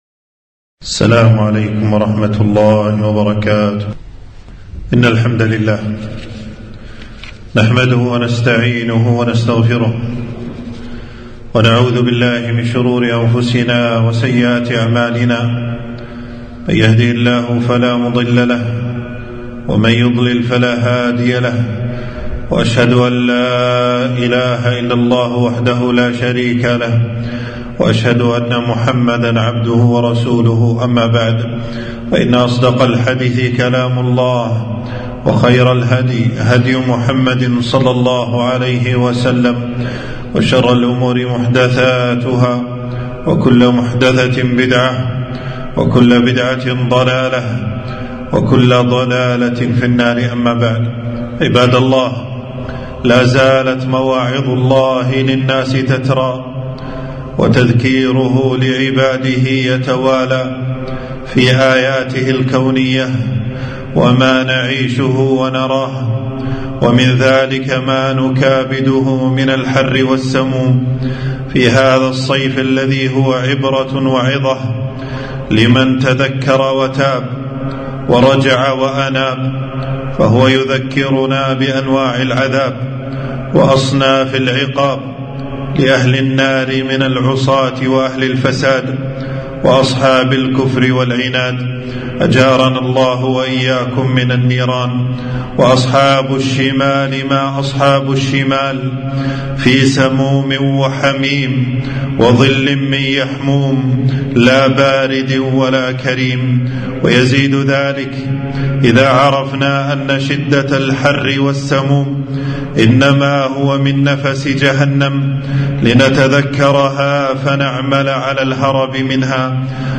خطبة - حر الصيف يذكرنا النار وعذابها - دروس الكويت